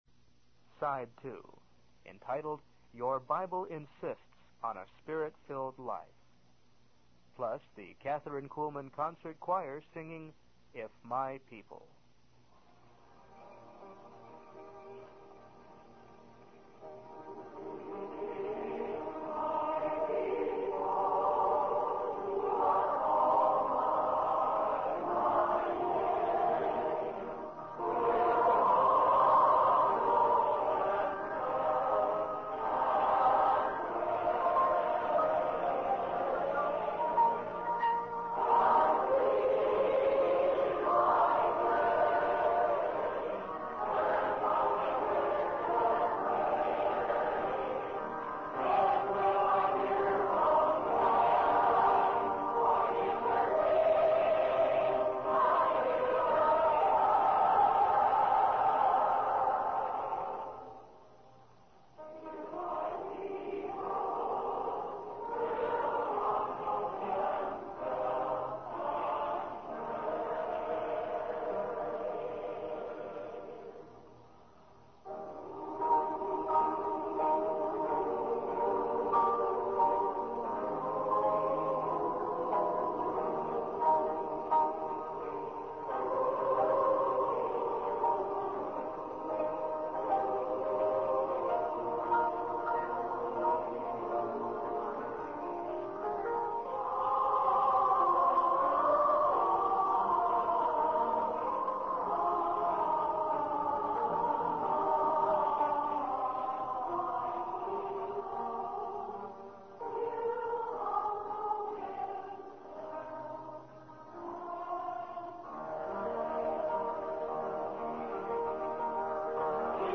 In this sermon, the speaker emphasizes the importance of living a spirit-filled life as instructed in the Bible.